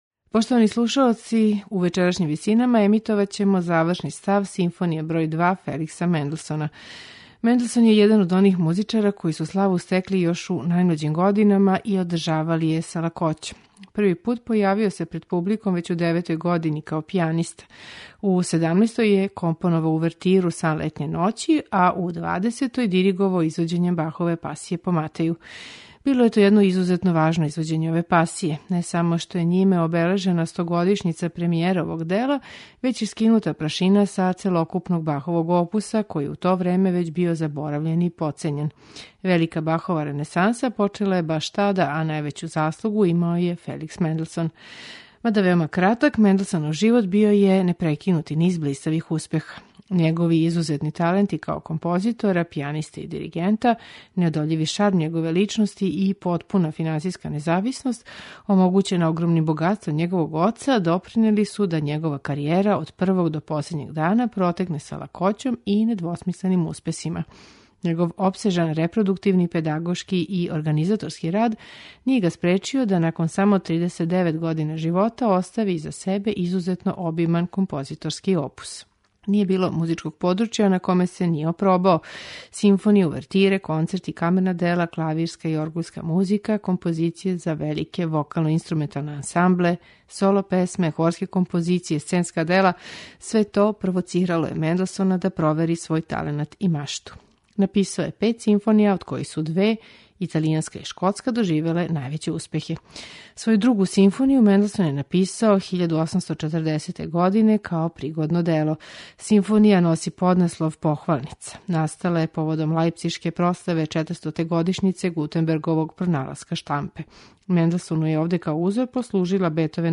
Као и његов велики узор, и Менделсон је осмислио финални став великих димензија, у који је укључио бројне вокалне нумере.
У вечерашњим Висинама , завршни став Друге симфоније у Бе-дуру, опус 52, Феликса Менделсoна, слушаћете у интерпретацији Хора лајпцишког радија и оркестра Gewandhaus , под управом Курта Мазура.